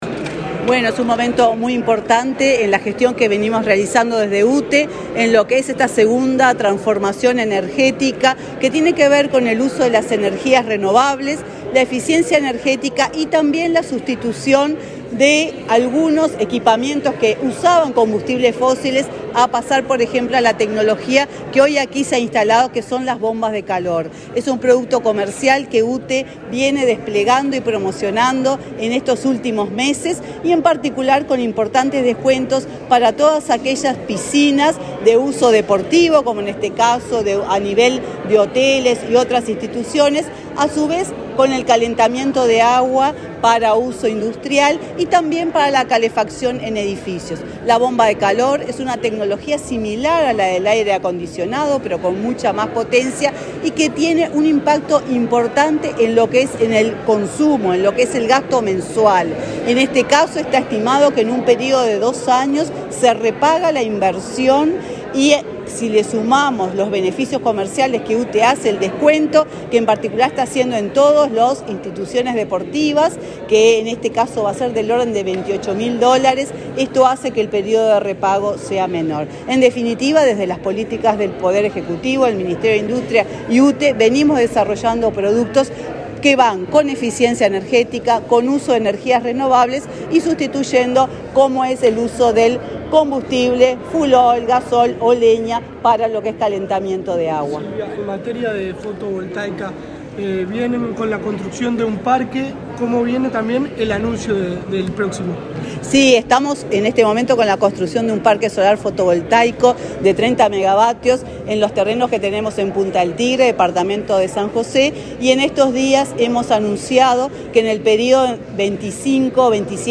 Declaraciones de la presidenta de UTE, Silvia Emaldi
Declaraciones de la presidenta de UTE, Silvia Emaldi 04/08/2023 Compartir Facebook X Copiar enlace WhatsApp LinkedIn La presidenta de la UTE, Silvia Emaldi, dialogó con la prensa, antes de inaugurar 10 plantas fotovoltaicas y bombas de calor en el departamento de Maldonado.